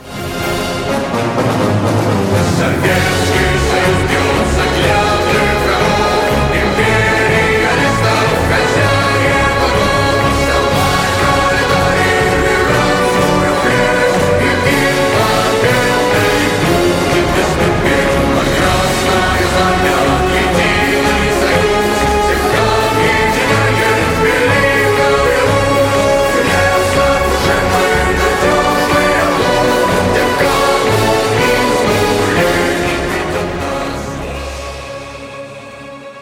Cover
хор
патриотические
марш
эпичные